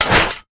ironShieldBash.ogg